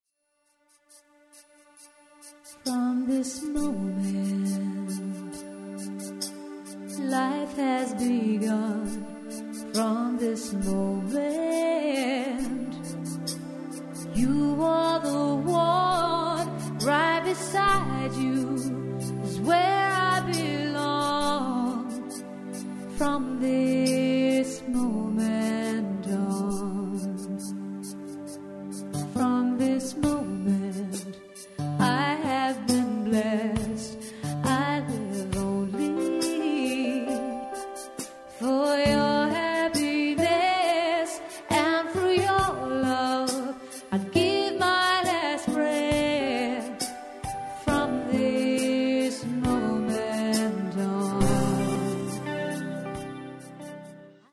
• Solo Singer